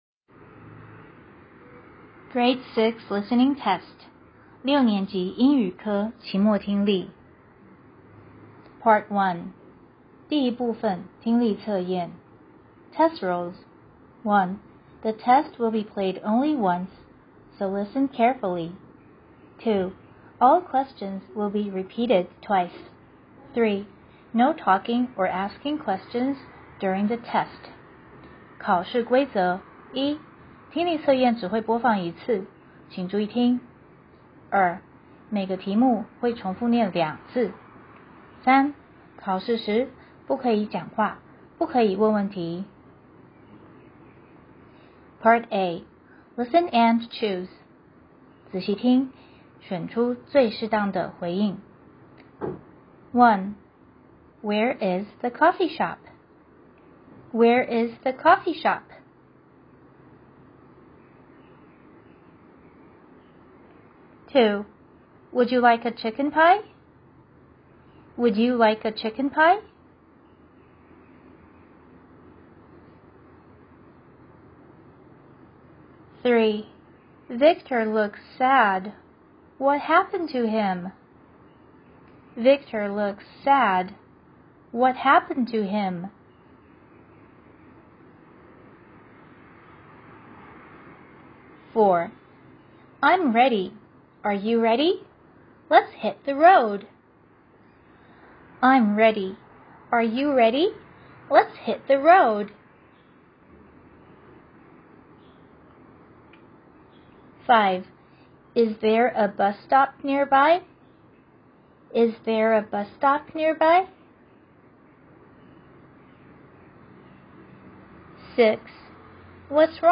六年級畢業考英聽檔